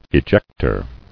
[e·jec·tor]